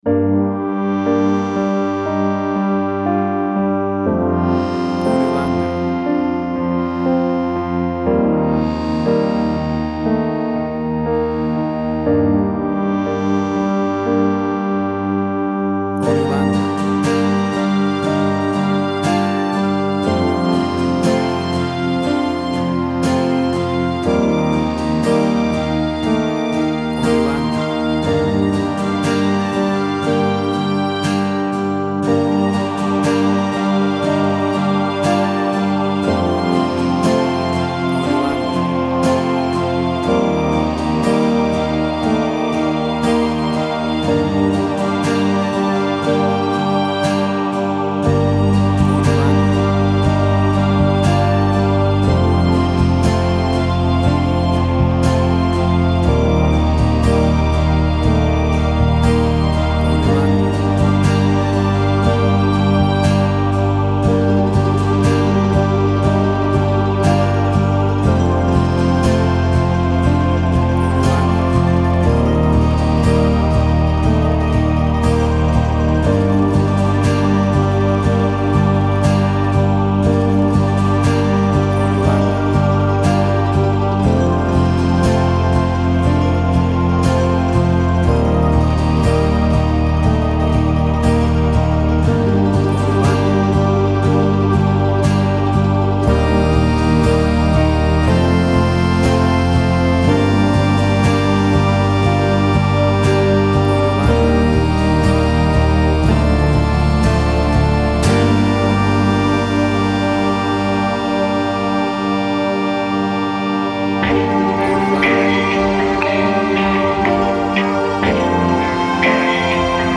Post-Electronic.
Tempo (BPM): 60